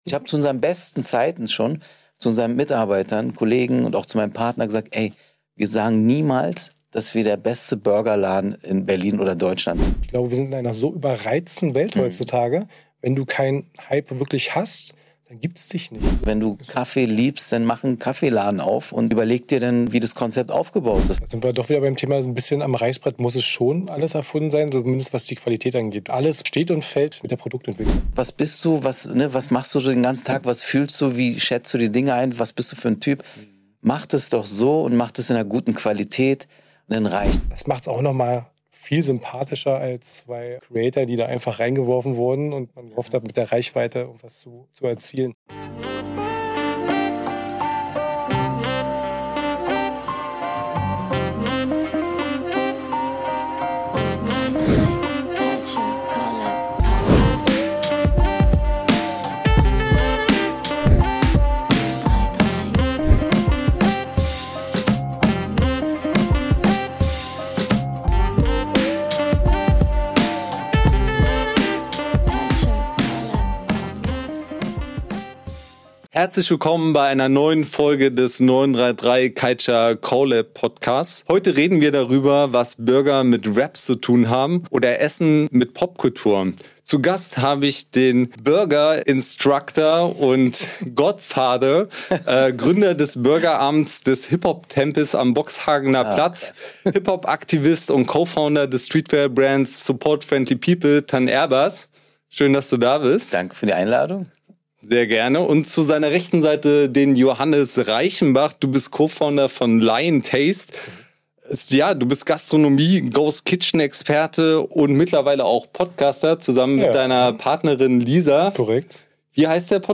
Das Gespräch führt von der Entstehung von Burgeramt über Kollaborationen mit kulturellem Fit bis hin zu aktuellen Phänomenen wie Loco Chicken, Milano Vice, MrBeast Burger und Ghost Kitchens. Es geht um die Frage, warum Reichweite allein keine Relevanz schafft, warum viele Läden verschwinden, während andere bleiben und echte Relevanz Zeit braucht. _____________________________________________________  Bei 933 CULTURE CO:LAB trifft Culture auf Mindset – echte Gespräche mit kreativen Köpfen, die Großes bewegen.